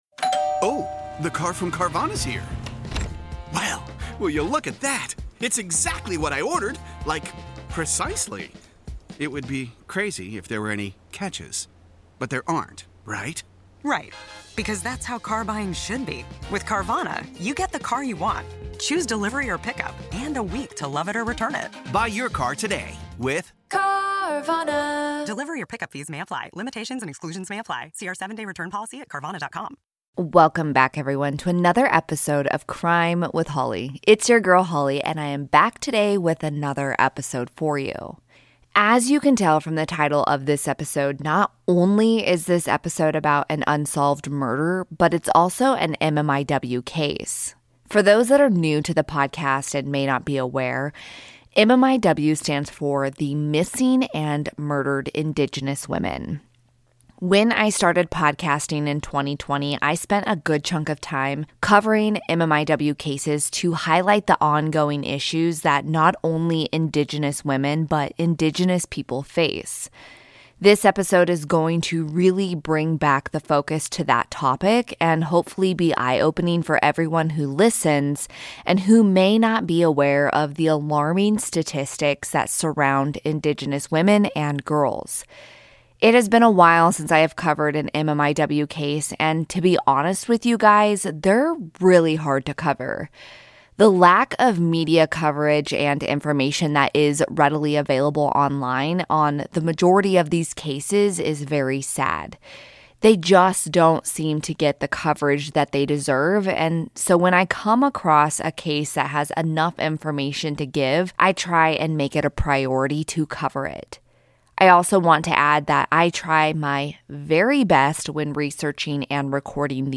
New introduction and ending have since been recorded and added, audio levels may reflect the different recordings.